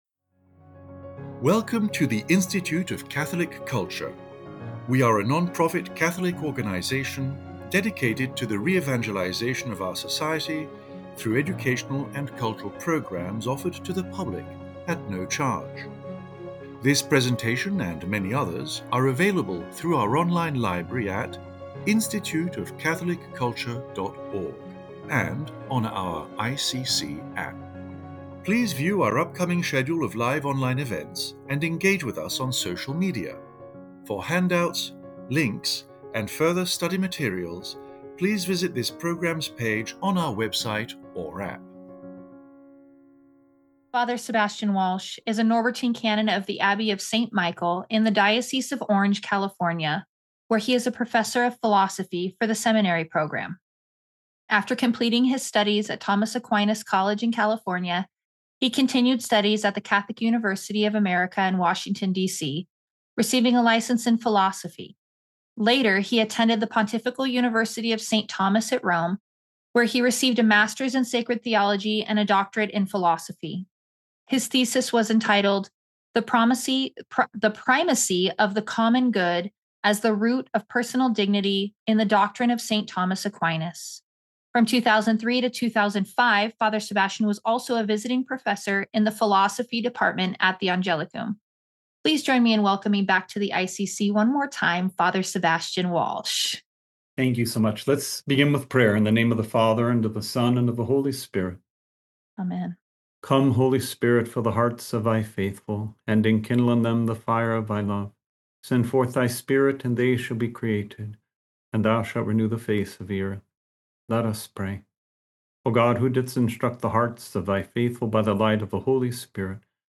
This lecture sets forth certain principles of Catholic Eschatology, drawing insights from the Catechism and Scripture.